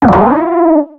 Cri de Haydaim dans Pokémon X et Y.